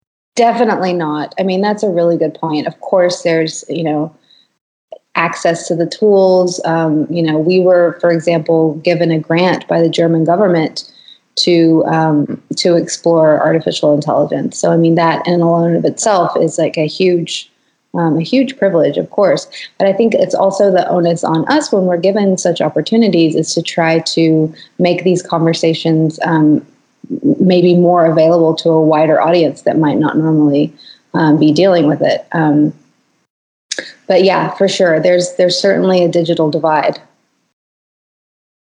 Interview: Holly Herndon on ‘PROTO’ and averting dystopia in the Protocol Era